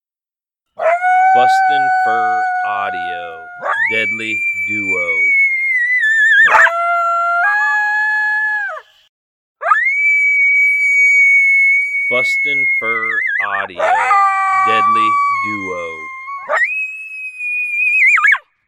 Pair howl from BFA's popular duo MotoMoto and Beans, both these Coyotes have a little swagger in their howls that fires up the Coyotes you're calling to.